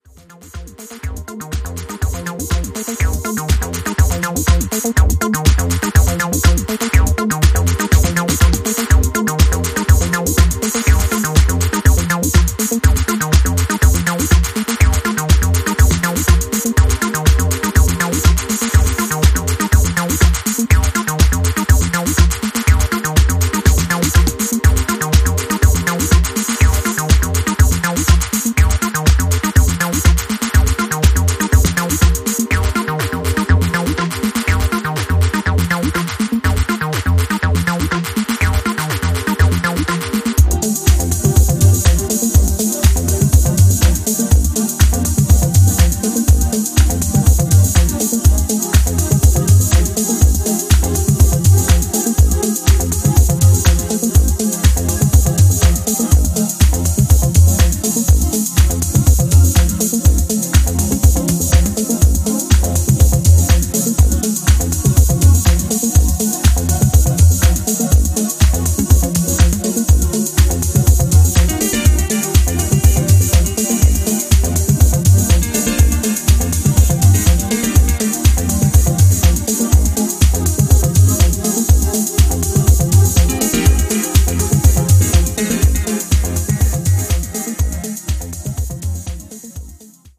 バレアリックやアシッド等の要素をセンス良くブレンドさせた、